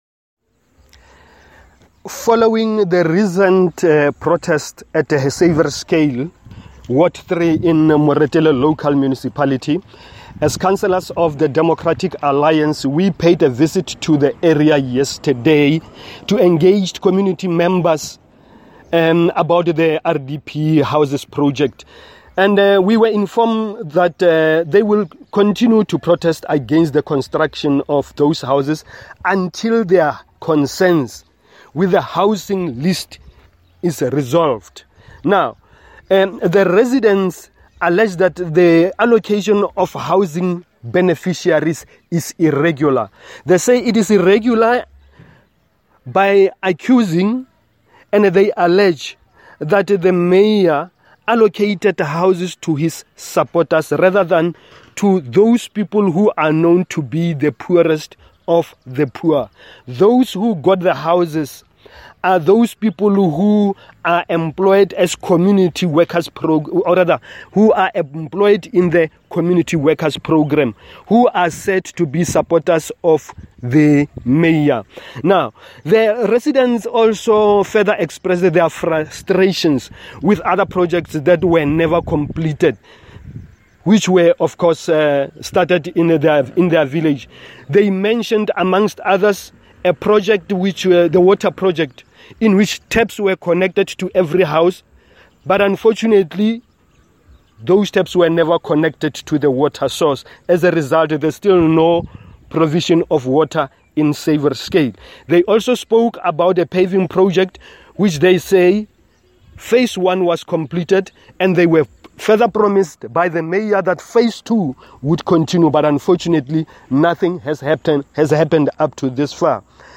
Note to Editors: Please find the attached soundbite in
Setswana from the DA Caucus Leader, Councillor Solly Magalefa.